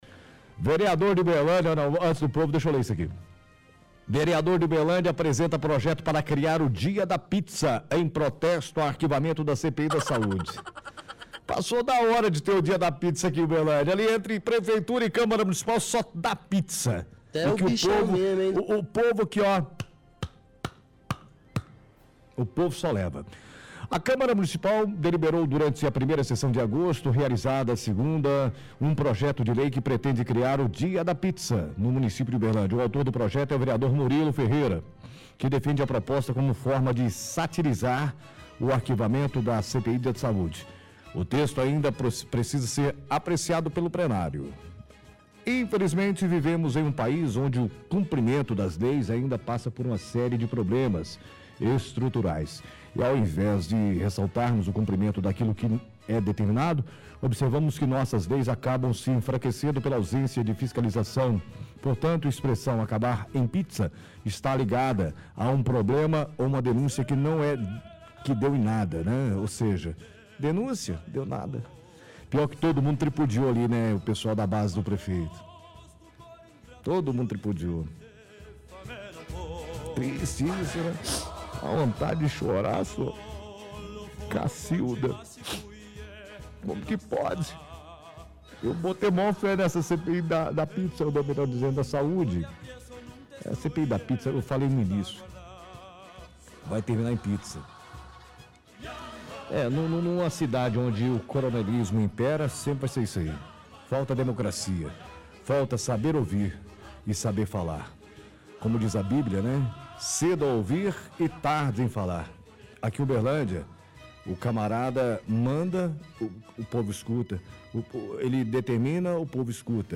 -Projeto é do vereador Murilo -Afirma que toda a base do Prefeito tripudiou -Faz deboche imitando choro.